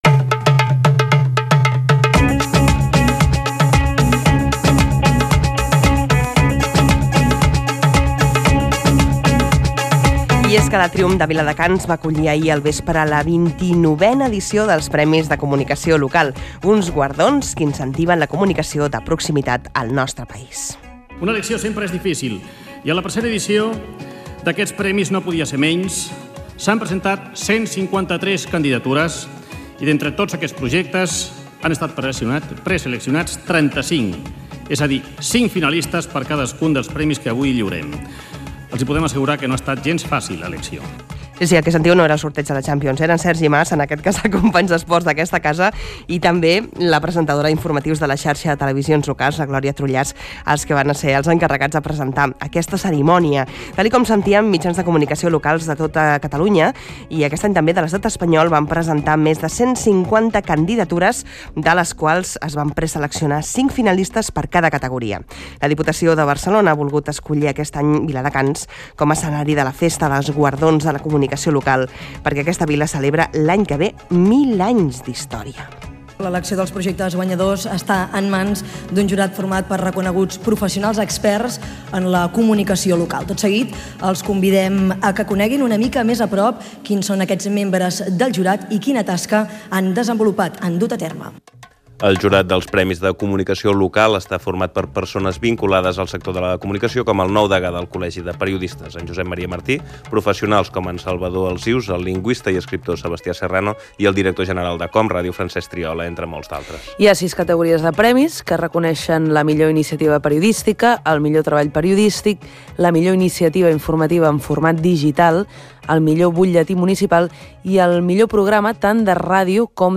Divulgació
Fragment extret de l'arxiu sonor de COM Ràdio